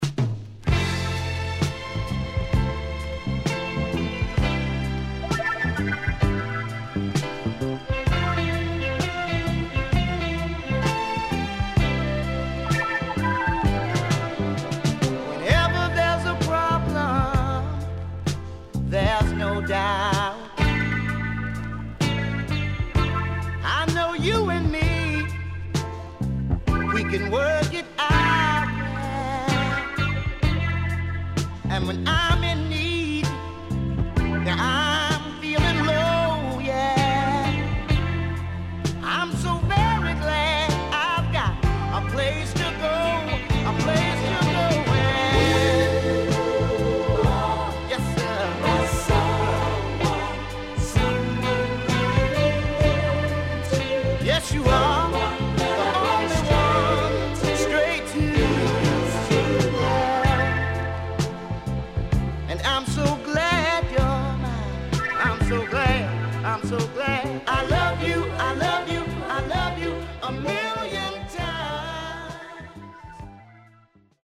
HOME > SOUL / OTHERS
SIDE A:少しノイズ入りますが良好です。